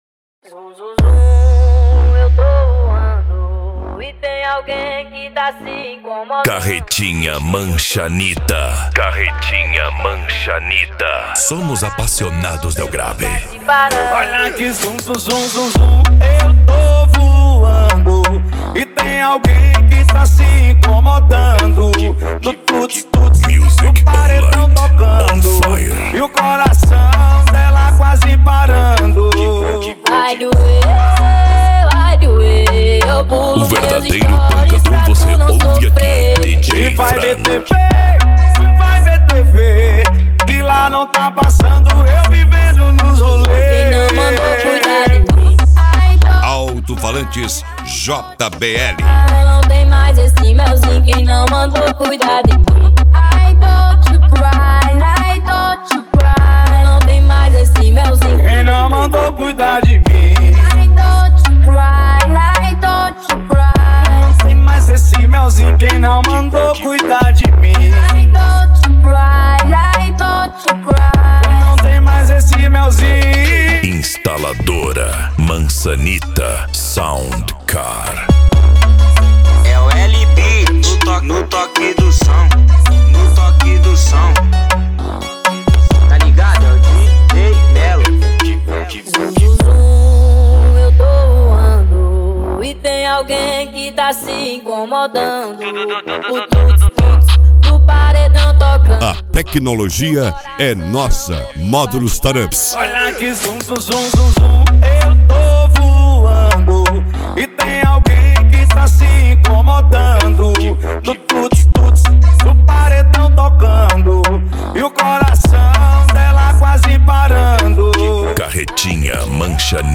Bass
Racha De Som